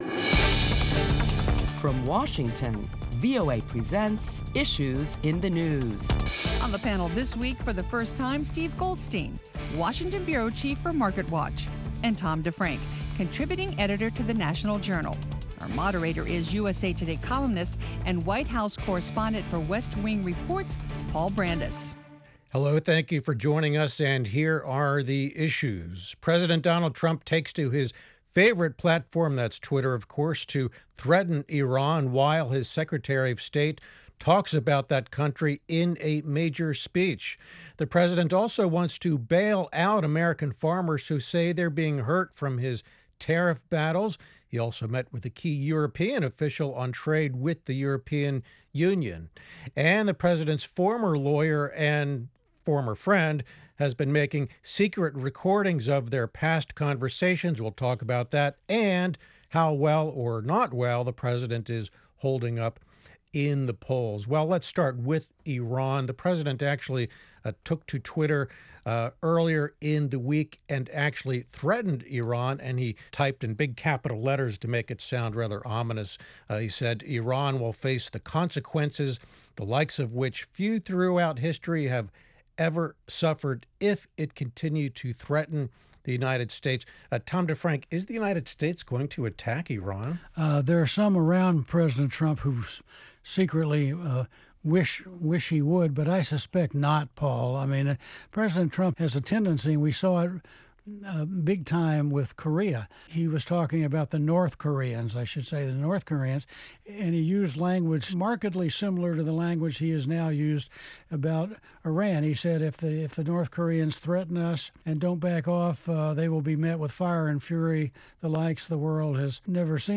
Top Washington correspondents discuss the week’s headlines including the latest saber rattling tweets from President Donald Trump and Iranian President Hasan Rouhani.